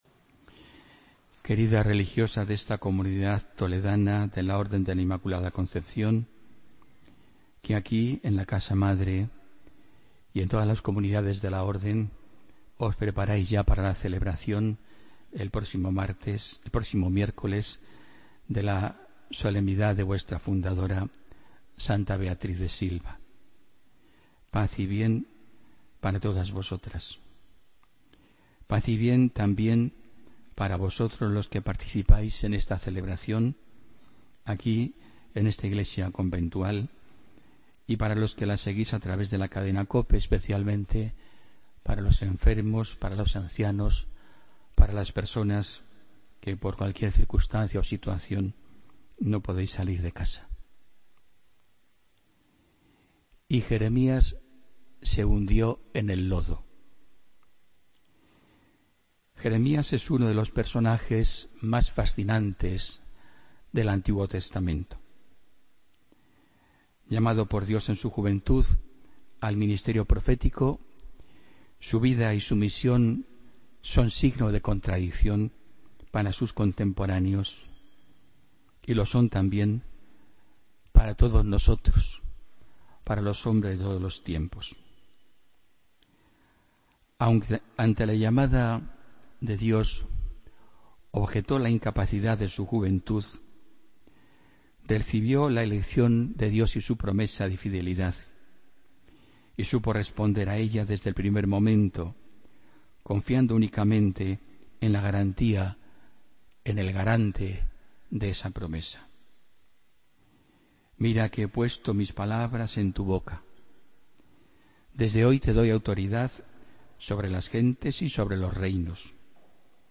Homilía del domingo, 14 de agosto de 2016